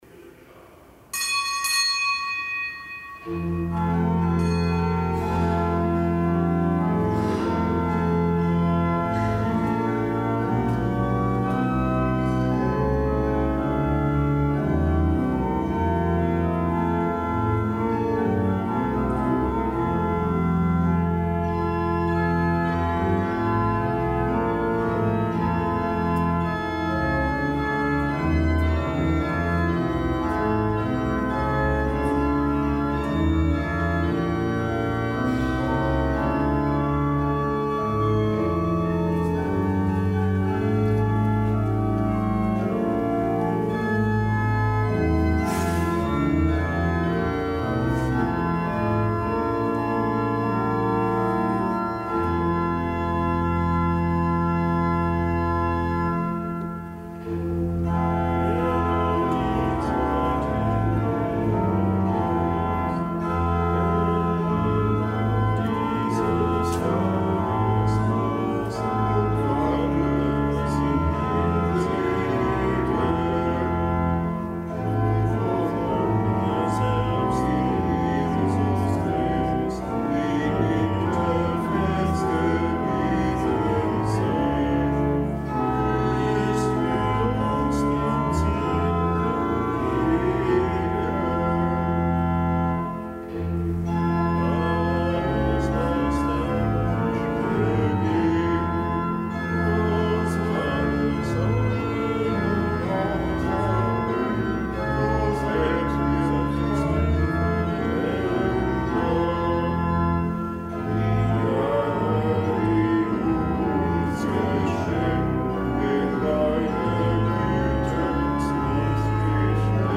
Kapitelsmesse am Freitag der Weihnachtszeit
Kapitelsmesse aus dem Kölner Dom am Freitag der Weihnachtszeit, dem nichtgebotenen Gedenktag Heiligster Name Jesu.